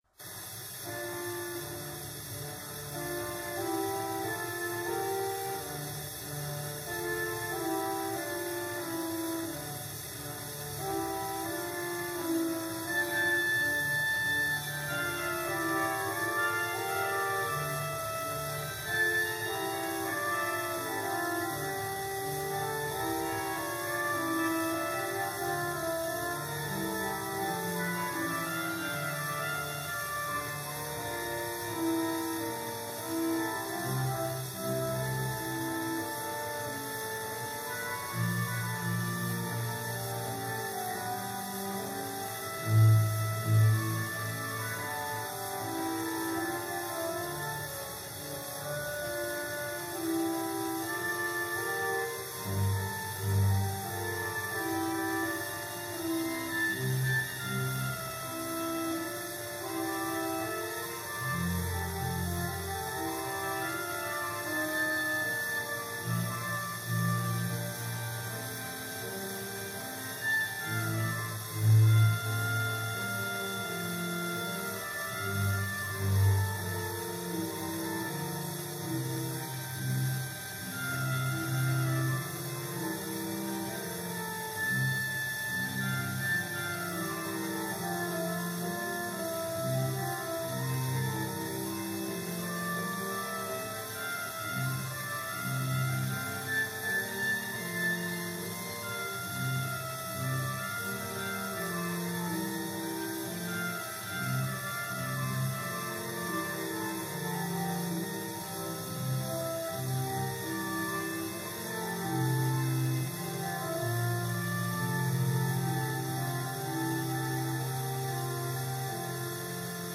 1931, disque 78 tours, 30 cm, Columbia DFX 233, Jean-Sébastien Bach :